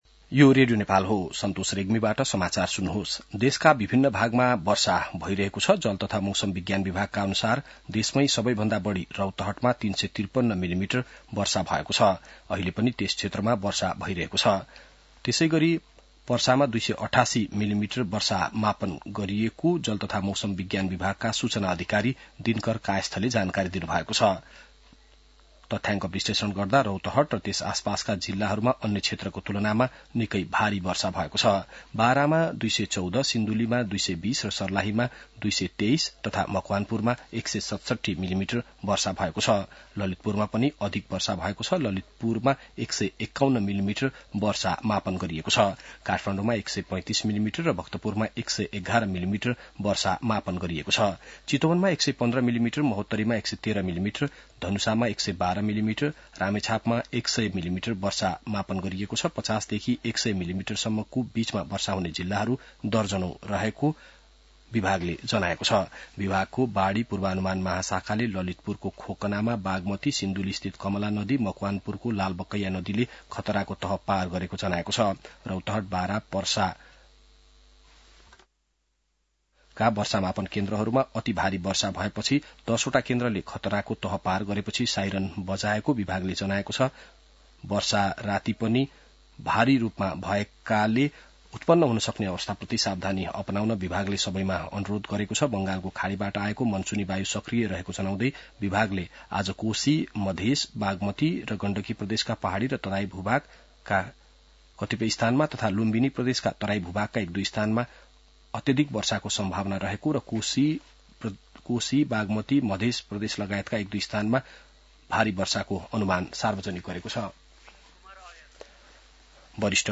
बिहान ६ बजेको नेपाली समाचार : २७ वैशाख , २०८२